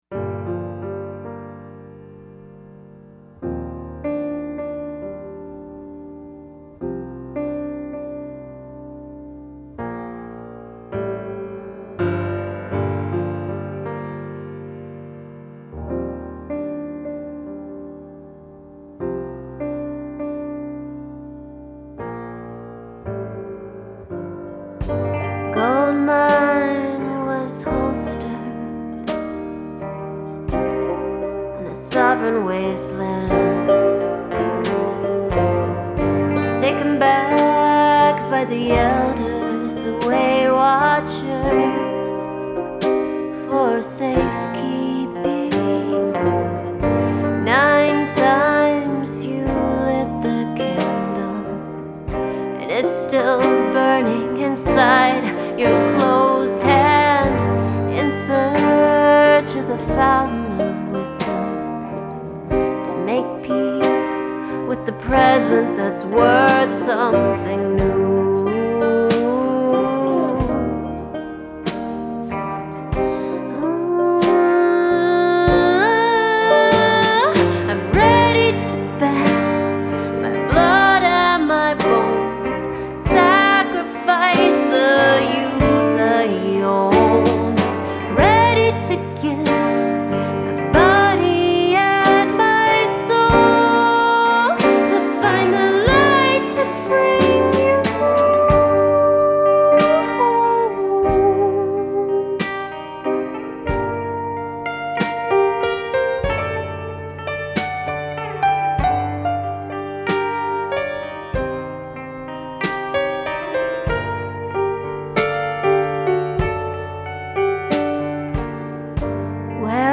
Alt-indie rock band
with bluegrass influences